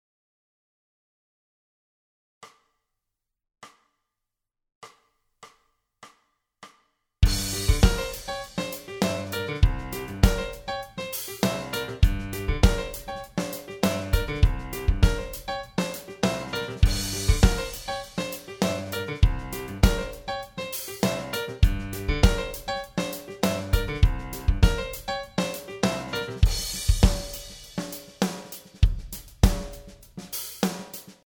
Funk `n` Soul Riff 14